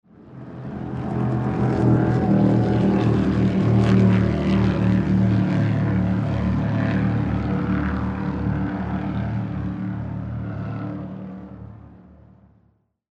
Рев скоростной моторной лодки